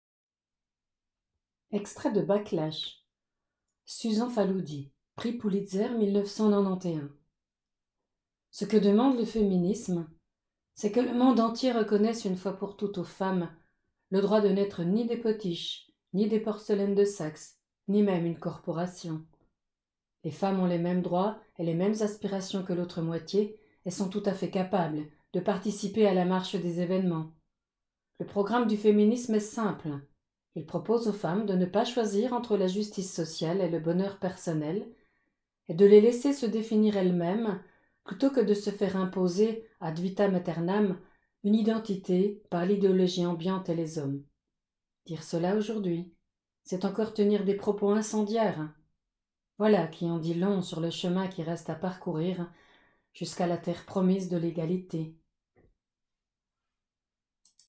Essai romancé
Voix de l'auteure